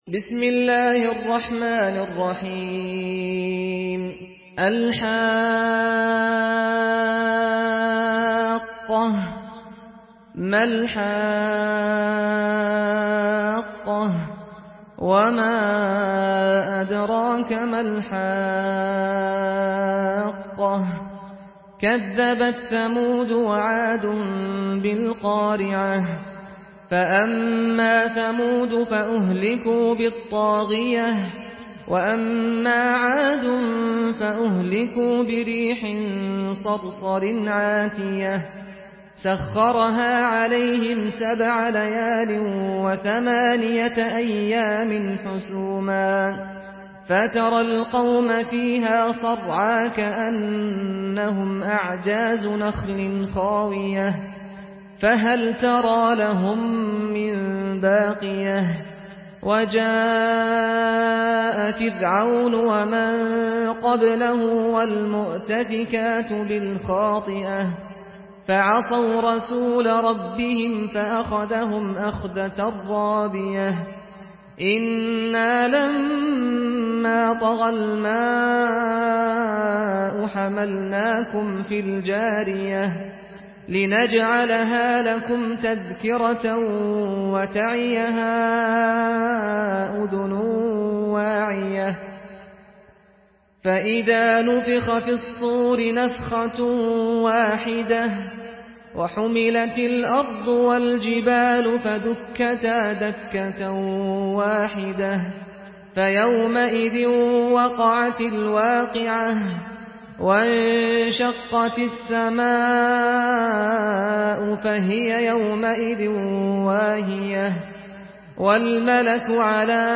Surah Al-Haqqah MP3 by Saad Al-Ghamdi in Hafs An Asim narration.